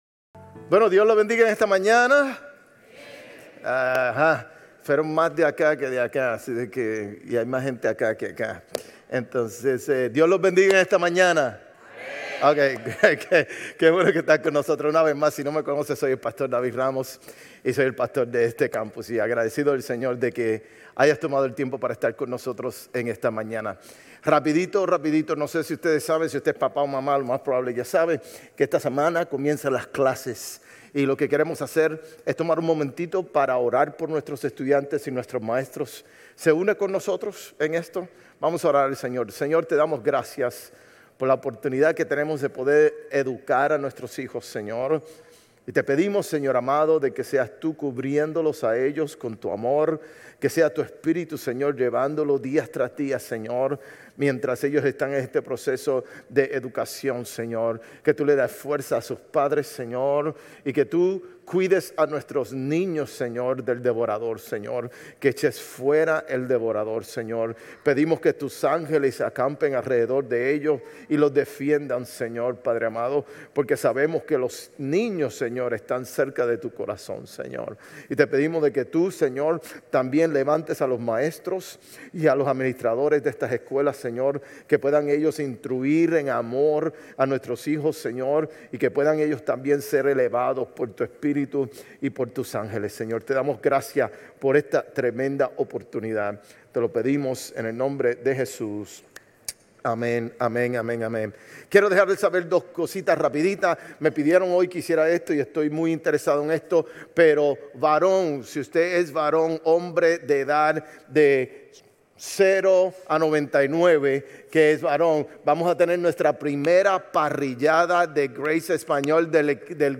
Sermones Grace Español 8_10 Grace Espanol Campus Aug 11 2025 | 00:44:14 1x 00:00 / 00:44:14 Subscribe Share RSS Feed Share Link Embed